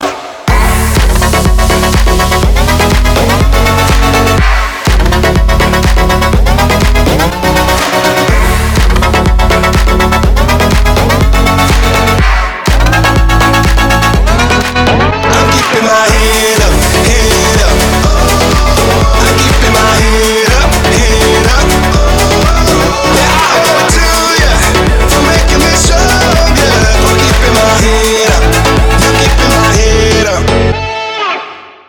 мужской вокал
громкие
Electronic
EDM
Стиль: future house